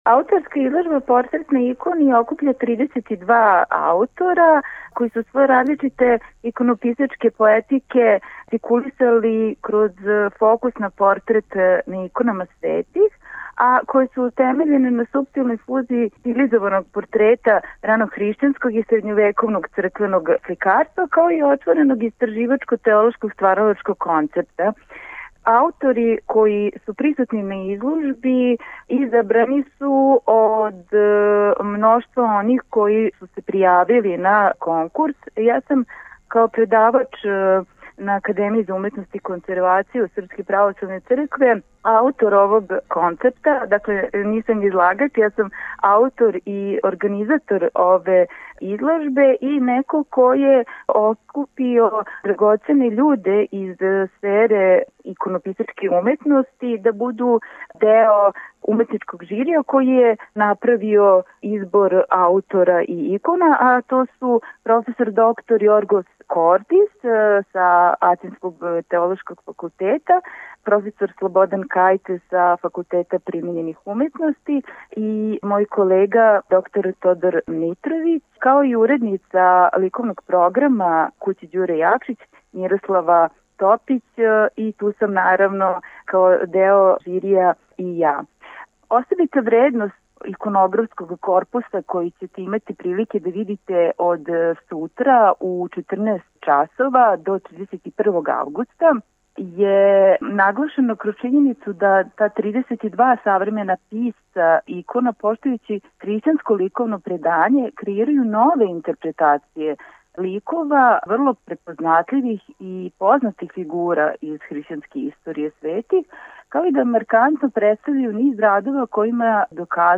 Звучни запис разговора Проф.